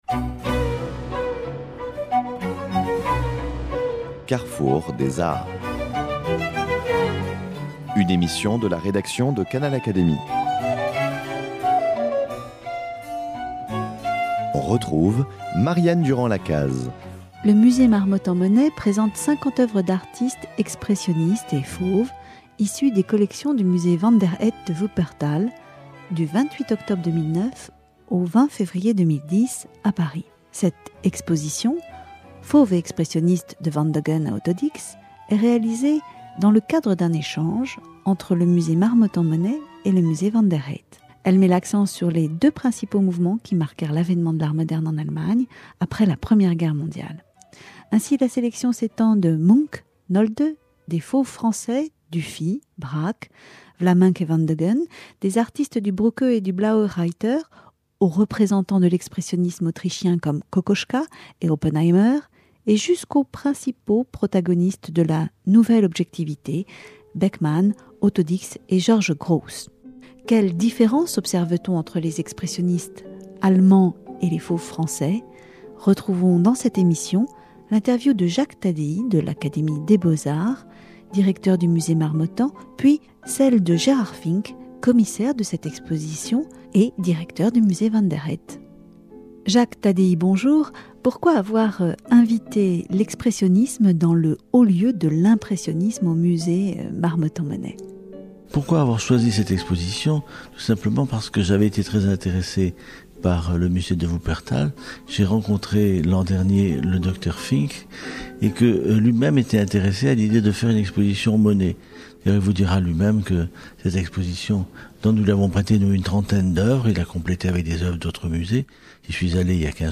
membre de l’Académie des beaux-arts s’expriment, au cours de cet entretien, sur cette confrontation aux sources d’un art moderne.